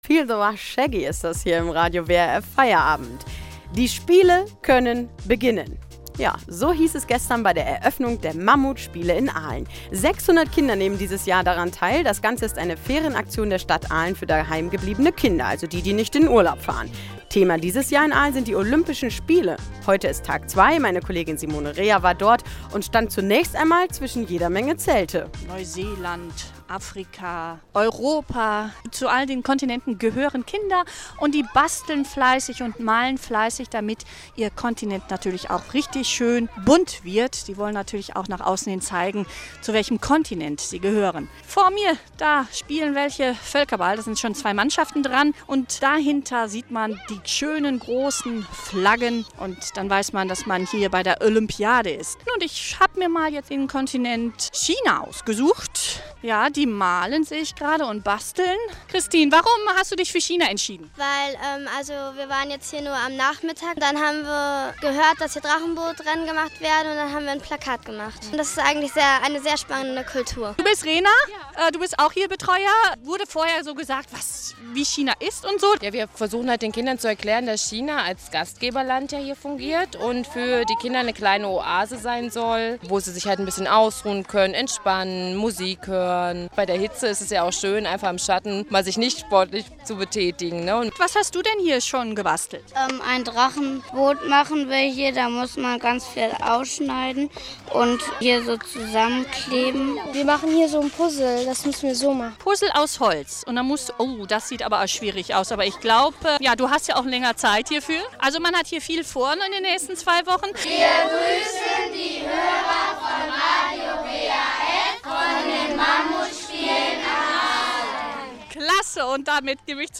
Radiowafbeitrag zu den Mammutspielen.